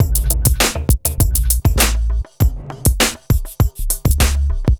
TSNRG2 Breakbeat 015.wav